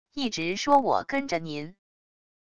一直说我跟着您wav音频生成系统WAV Audio Player